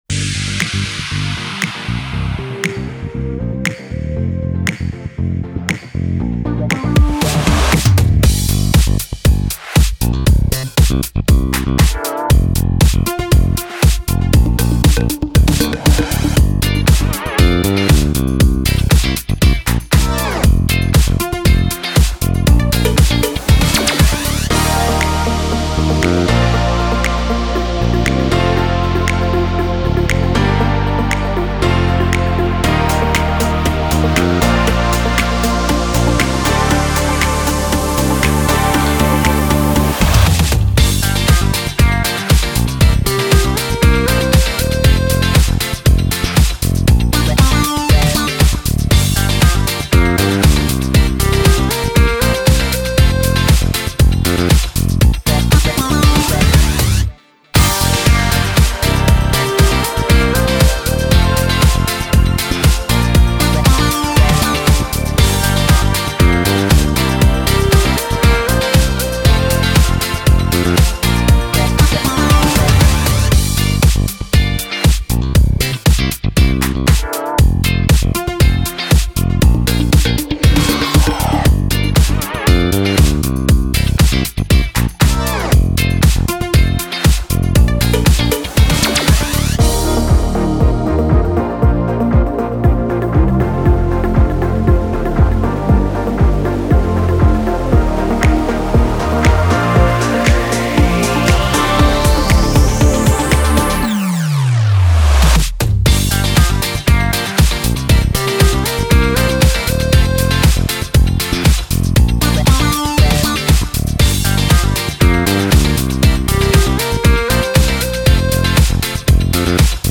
Характер песни: позитивный.
Темп песни: быстрый.
• Минусовка